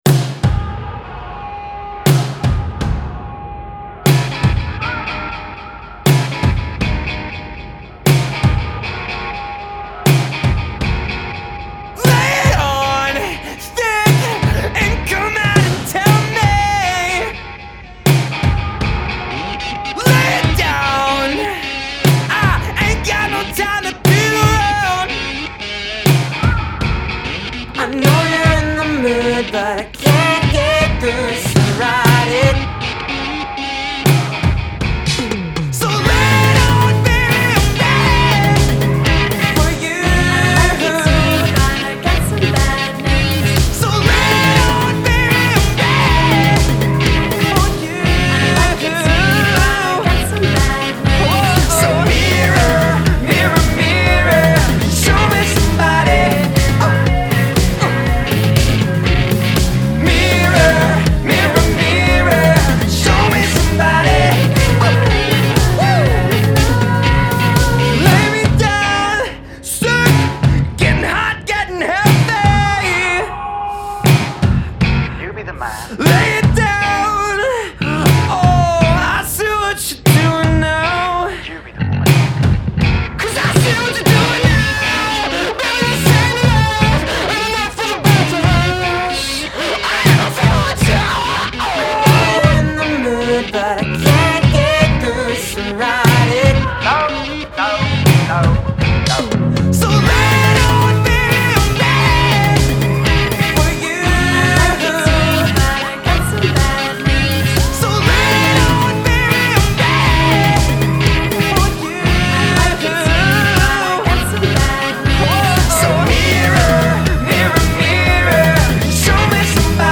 Vocals
Drums
Guitar, Bass, and Synths